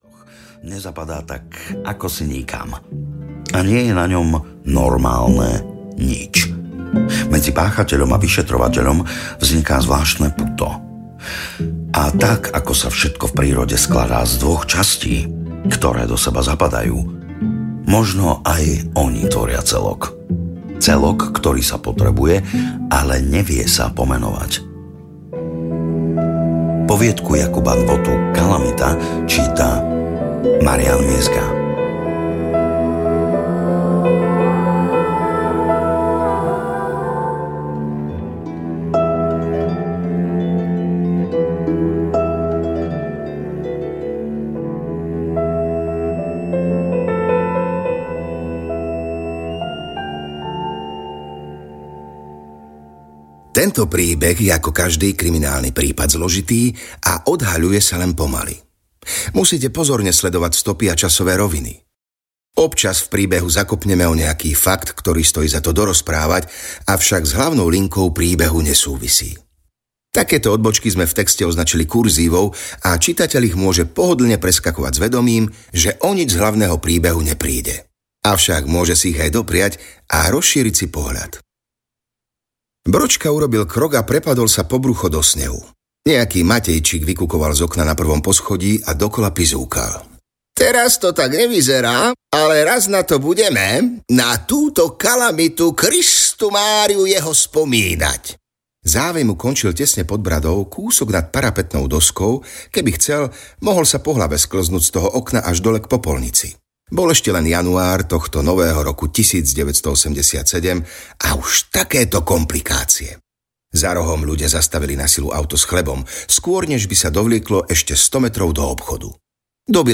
Slovensko KRIMI audiokniha
Ukázka z knihy